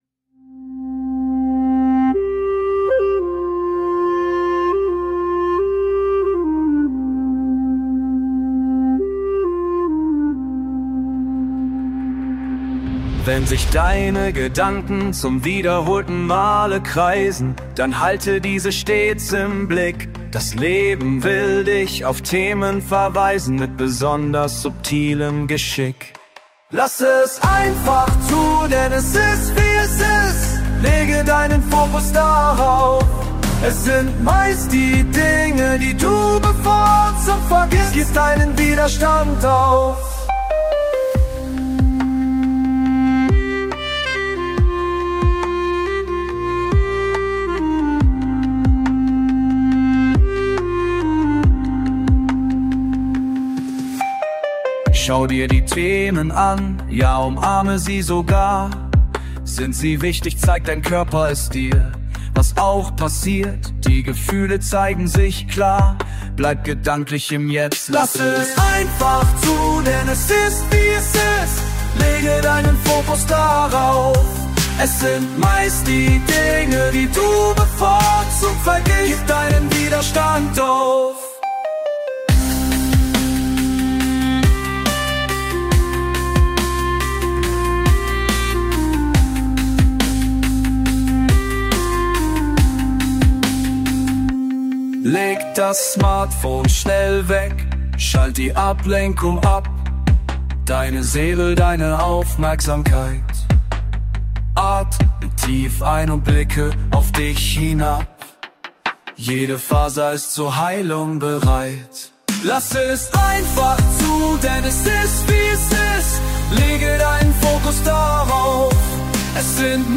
Asian Orchestral